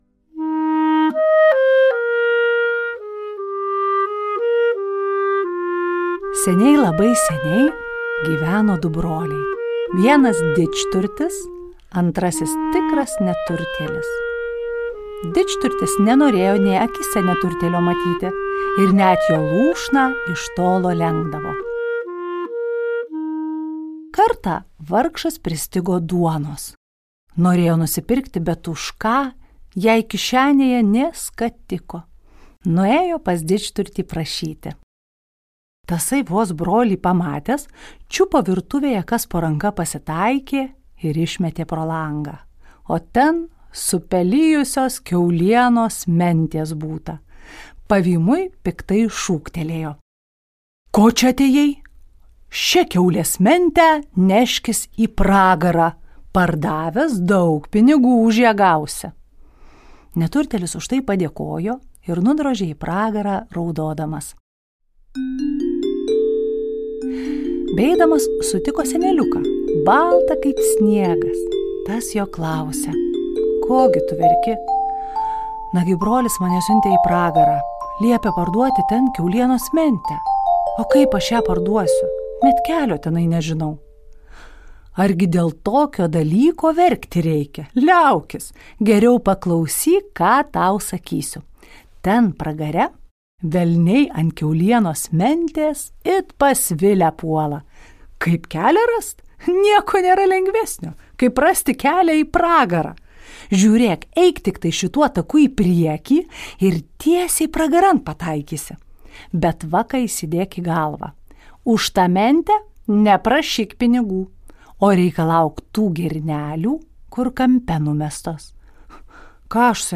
Pasakoje skamba kalimba, skambantys blokeliai, taip pat smulkūs perkusiniai instrumentai – griaustinio, jūros, šulinukas ir kiti